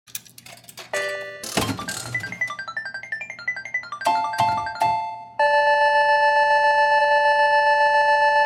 18. Монетка, рычаг, слоты и бонус